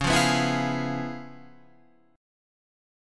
C#7#9 chord